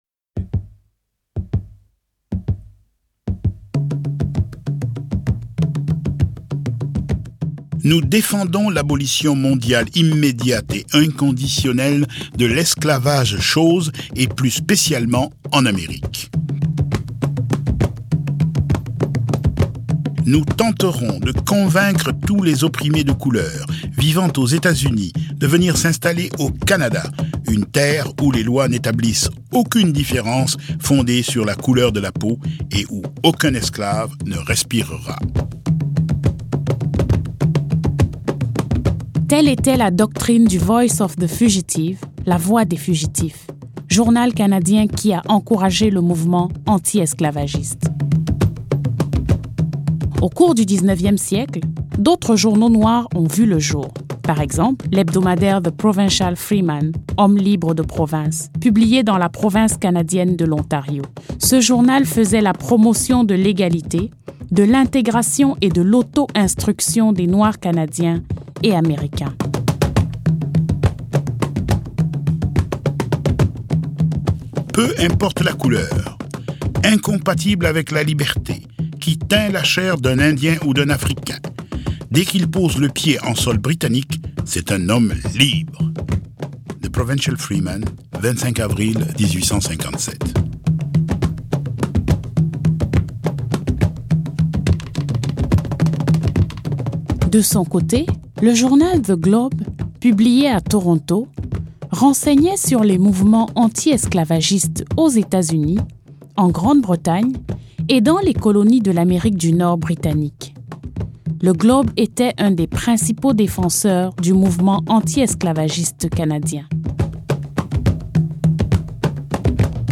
Narrateurs